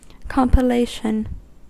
Ääntäminen
Synonyymit compilement Ääntäminen US UK : IPA : /kɒmpɪˈleɪʃən/ Lyhenteet ja supistumat comp.